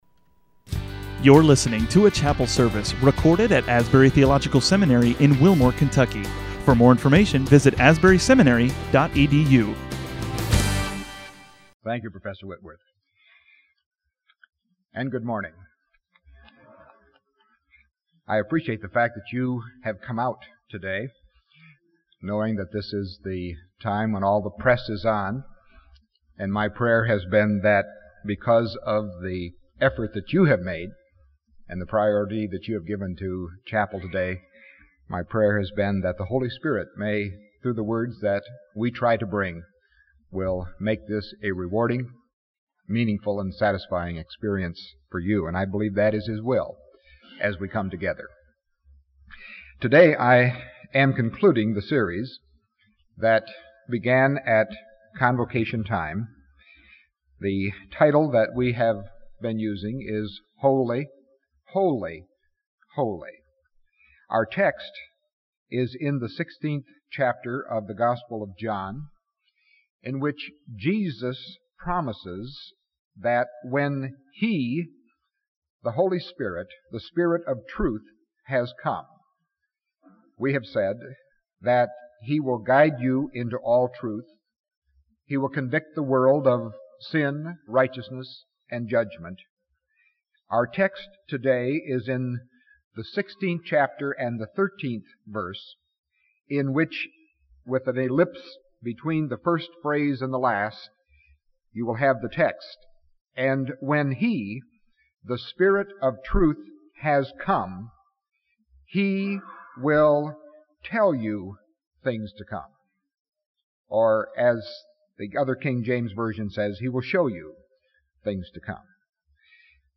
Holy week service (Apr. 1, 1980)